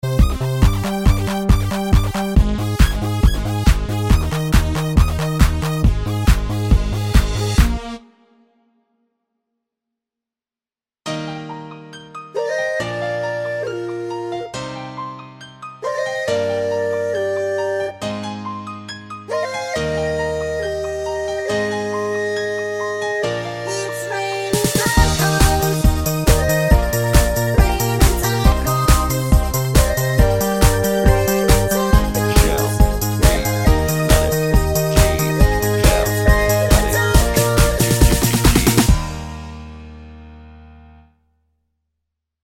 no Backing Vocals Comedy/Novelty 1:32 Buy £1.50